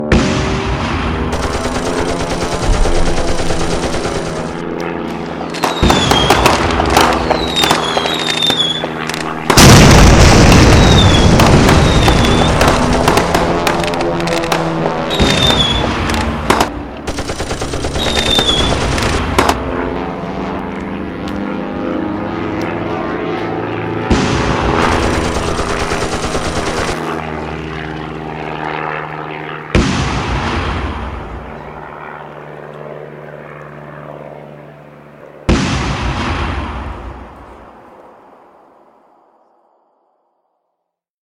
7. Wartime Battle Sounds